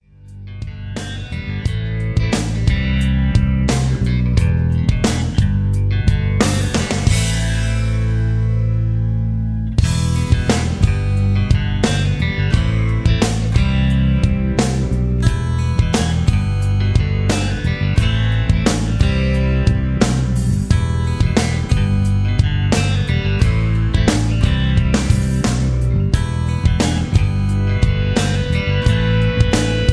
(Version-1, Key-Em) Karaoke MP3 Backing Tracks
Just Plain & Simply "GREAT MUSIC" (No Lyrics).